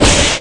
Thunder2.ogg